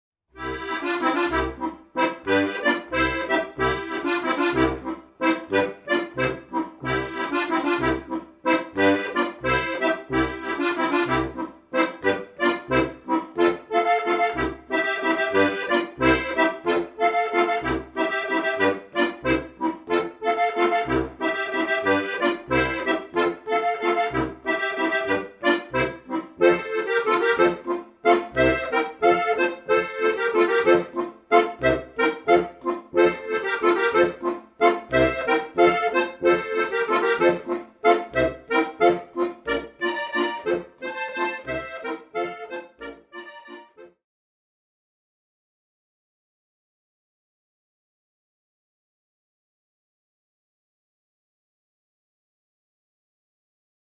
Besetzung: Steirische Harmonika